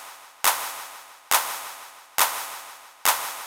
CLP REVERB-R.wav